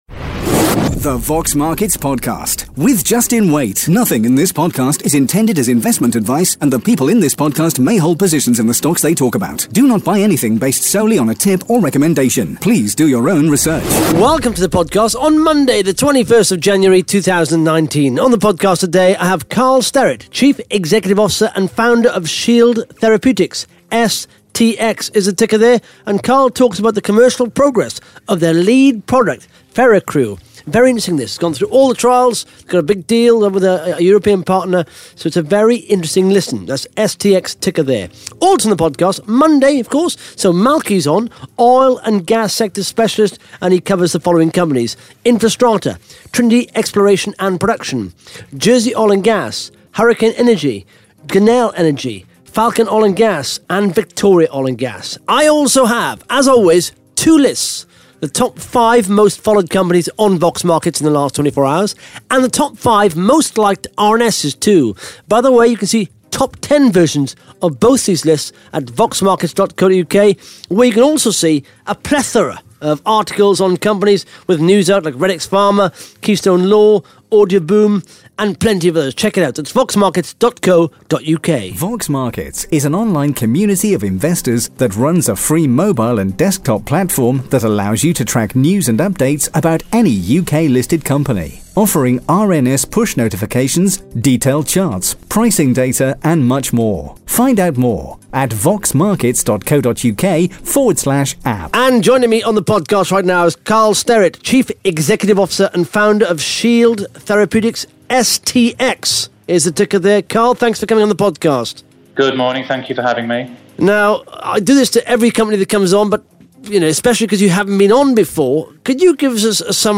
(Interview starts at 13 minutes 23 seconds) Plus the Top 5 Most Followed Companies & the Top 5 Most Liked RNS’s on Vox Markets in the last 24 hours.